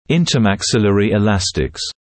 [ˌɪntəmæk’sɪlərɪ ɪ’læstɪks][ˌинтэмэк’силэри и’лэстикс]межчелюстные эластичные тяги, межчелюстные эластики